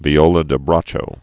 (vē-ōlə də brächō)